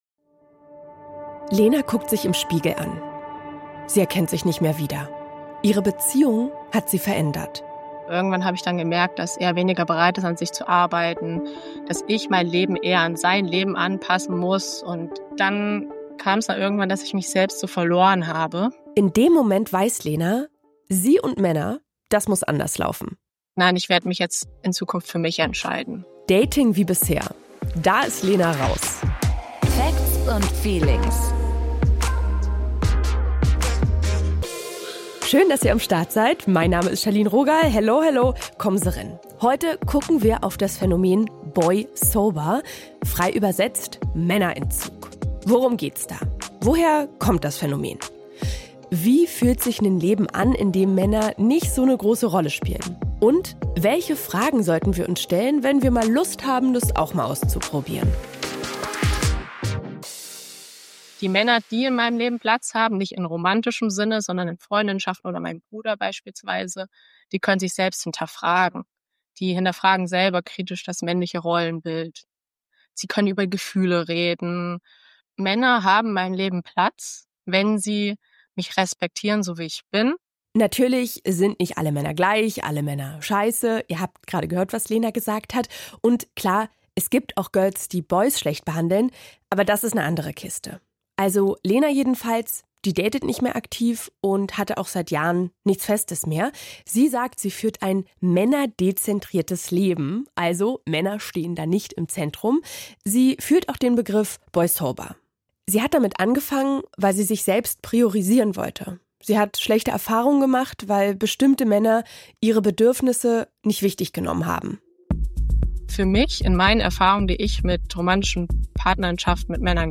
Psychologin, forscht zu Medien, Sexualität und Gender
Paartherapeutin, Systemische Beratung, Single-Beratung